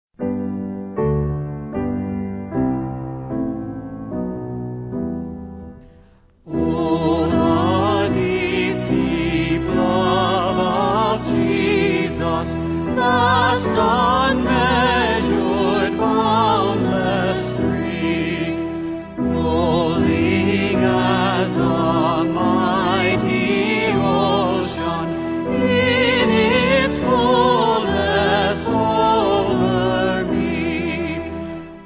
Choir